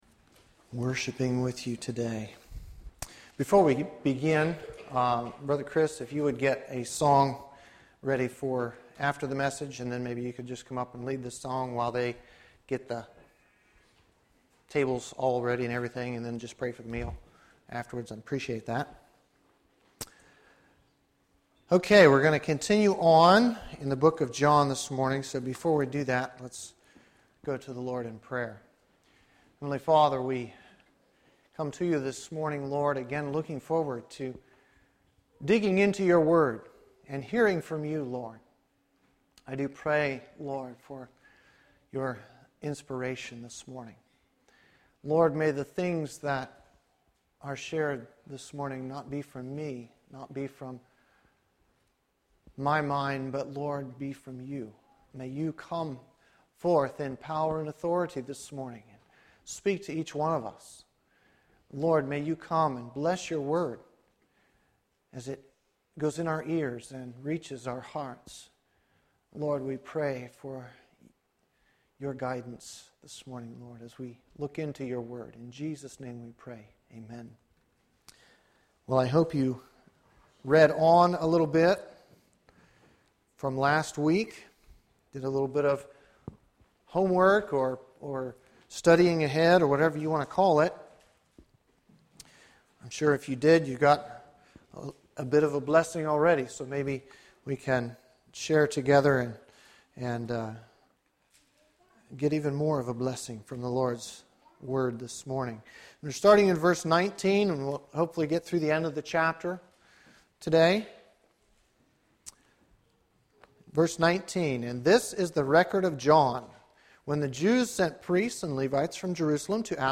In this sermon, the preacher focuses on the passage in John 1:19-51. The sermon is divided into three main points.